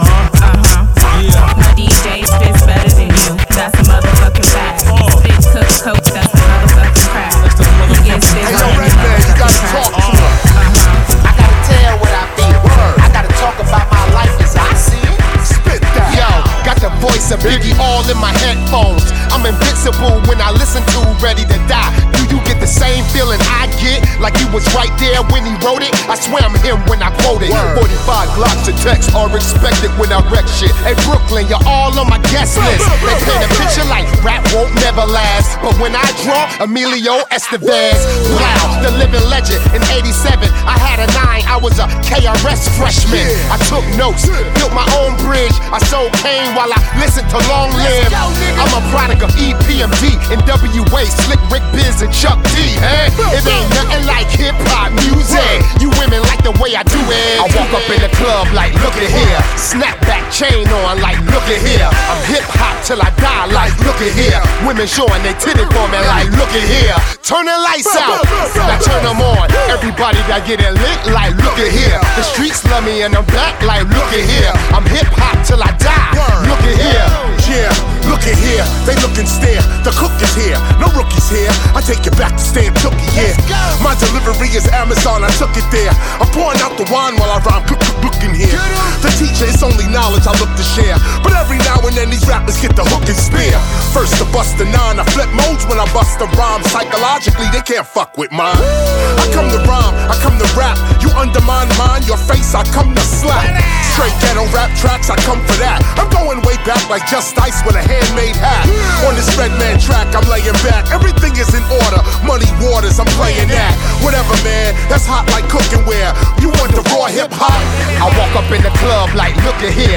Genre: Blues.